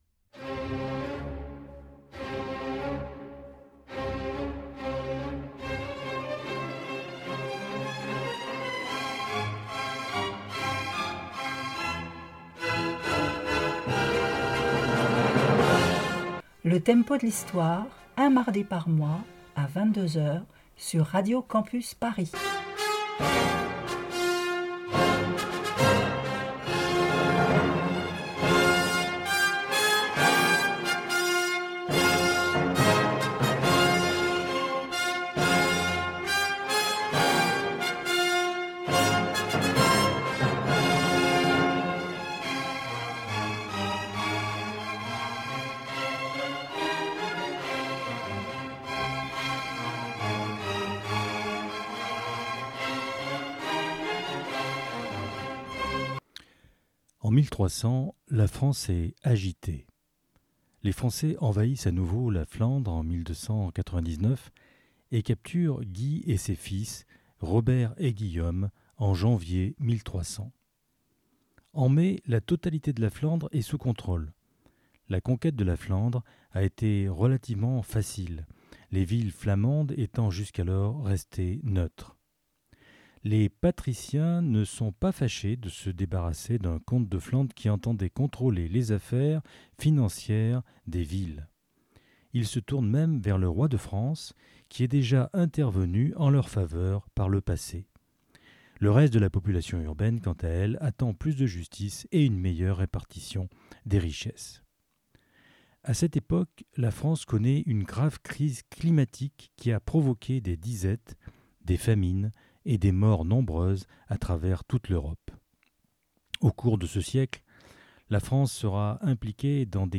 Philippe le Bel et l'affaire des templiers, portrait de denis Diderot, l'assassinat de martin Luther King le 4 avril 1968 . Côté musique ,l'Ars Nova avec guillaume de Machaut,jean philippe Rameau en 1757 ,la première de la comédie musicale "Hair" et la dernière apparition de maurice Chevalier.
Pop & Rock